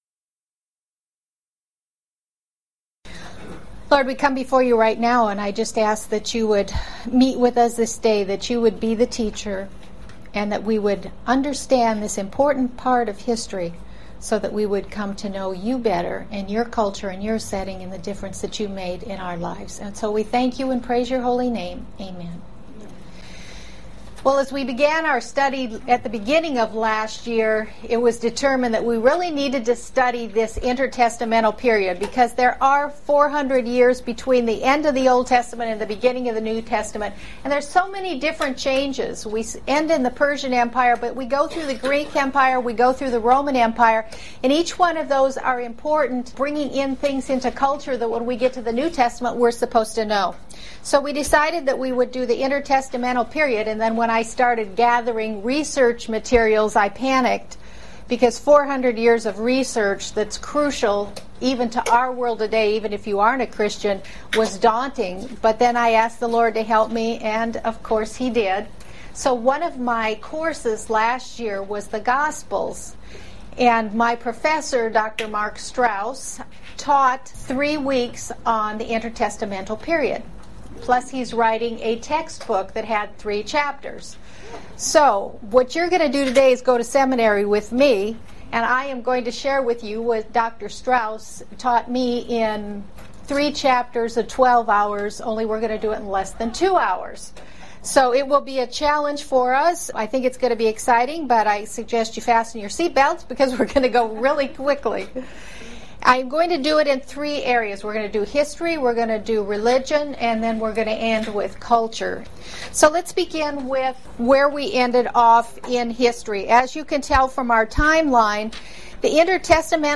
To help understand these major changes that effect our understanding of the New Testament, this lecture will very briefly highlight how these changes took place in history, religion and culture.
01_Intertestamental_Period_Lecture.mp3